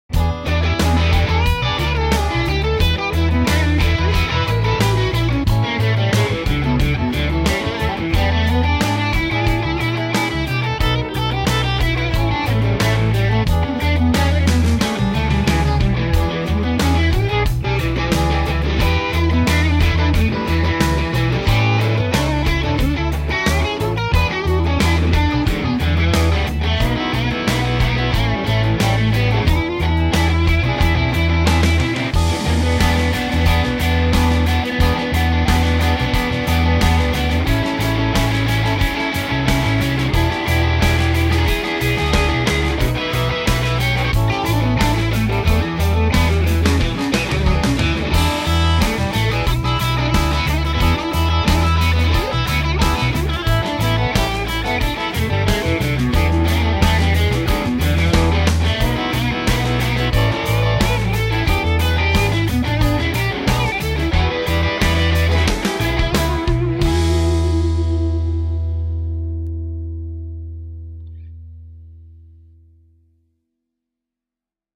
All RealTracks. Quintet. *** with Soloist ***
Bass, Electric
Guitar, Electric, Soloist TexasBluesRockNRollSol Ev 165\
Guitar, Electric, Rhythm ModernRnBRockFunky16ths Ev 065